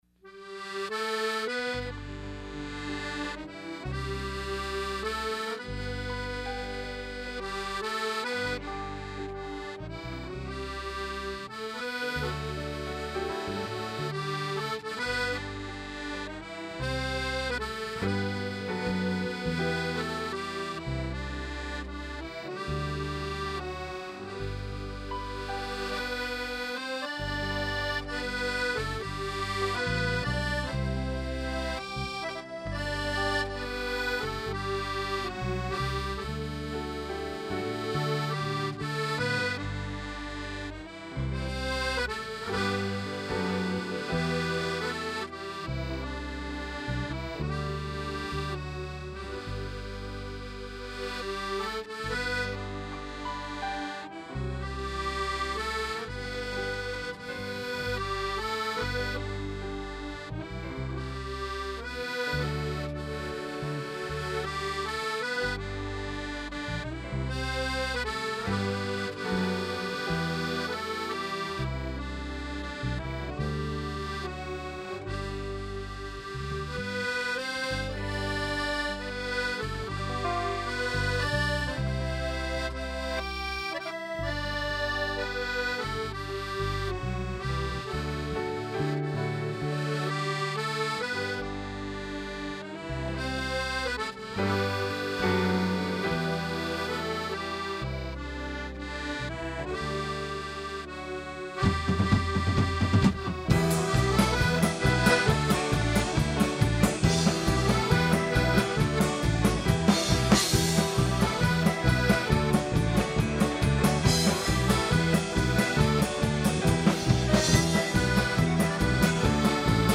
The Moonshiners Band was also invited to the Sunday afternoon show, for a live performance.